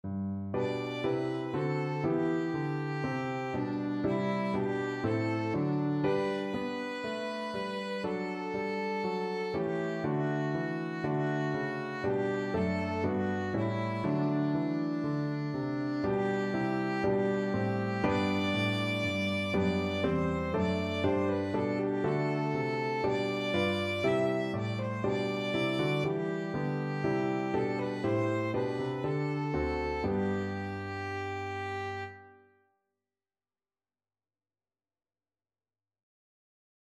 4/4 (View more 4/4 Music)
Allegro (View more music marked Allegro)
D5-E6
Classical (View more Classical Violin Music)